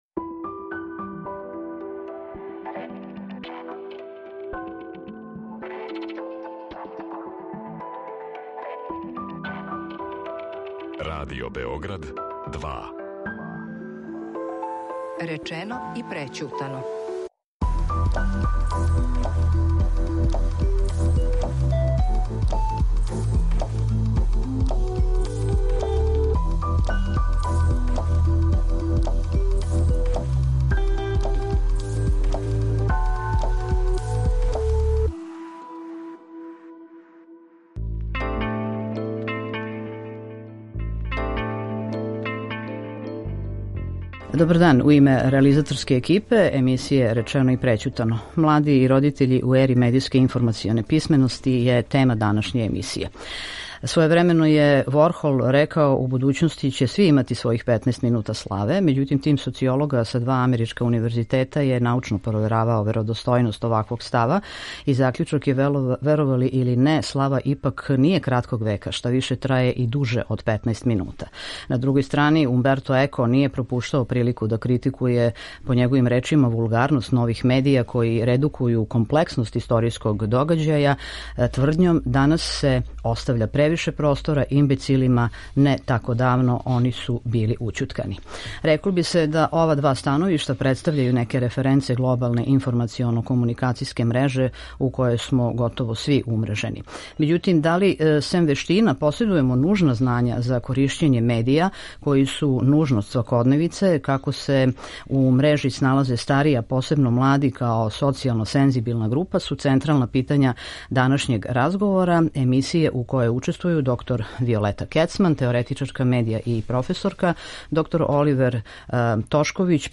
Међутим, да ли сем вештина поседујемо нужна знања за коришћење медија који су нужност свакодневице, како се у мрежи сналазе старији, а посебно млади као социјално сензибилна група, централна су питања данашњег разговора у коме учествују: